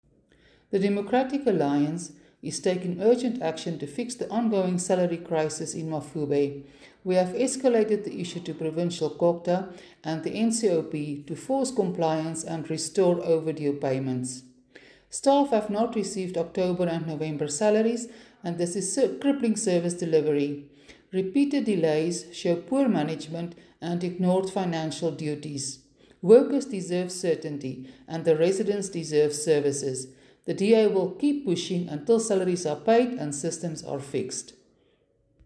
English and Afrikaans soundbites by Cllr Suzette Steyn and